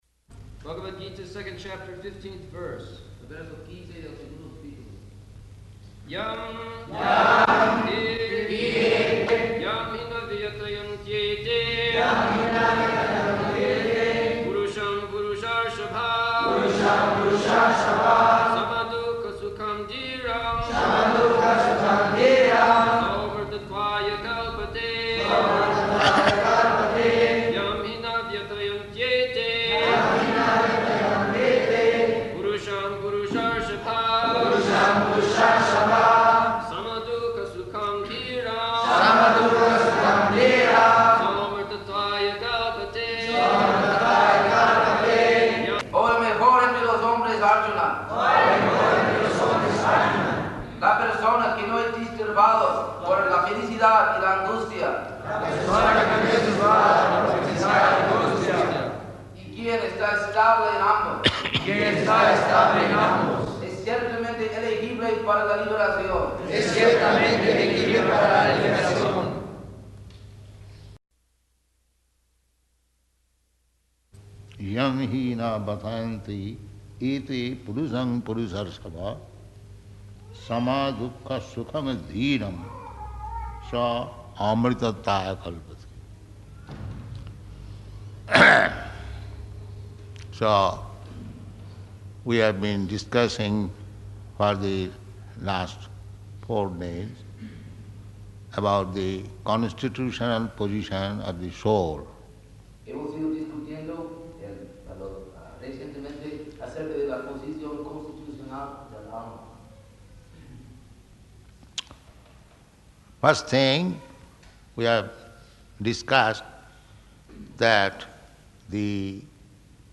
February 15th 1975 Location: Mexico City Audio file